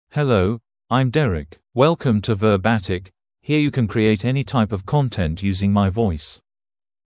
MaleEnglish (United Kingdom)
DerekMale English AI voice
Derek is a male AI voice for English (United Kingdom).
Voice sample
Derek delivers clear pronunciation with authentic United Kingdom English intonation, making your content sound professionally produced.